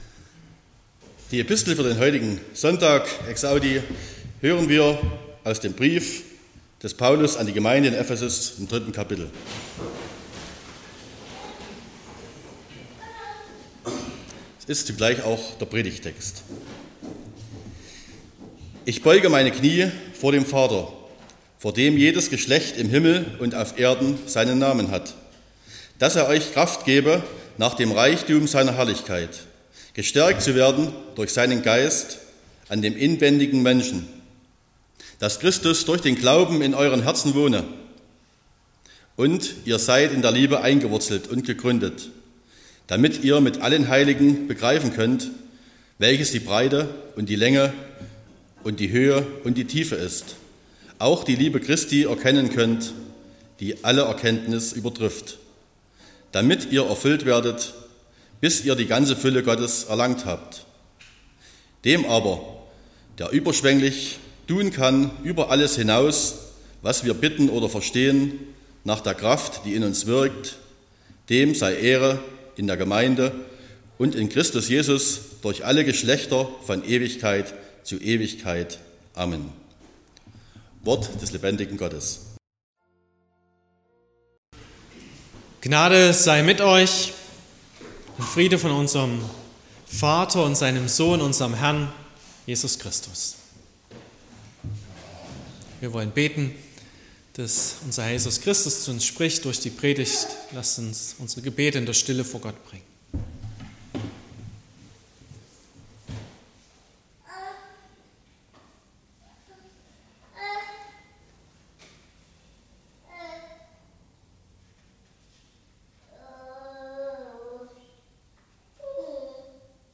01.06.2025 – Gottesdienst
Predigt (Audio): 2025-06-01_Immer_mehr_von_Dir_-_Im_Glauben_wachsen.mp3 (22,4 MB)